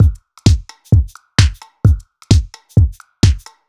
Index of /musicradar/uk-garage-samples/130bpm Lines n Loops/Beats
GA_BeatnPercE130-06.wav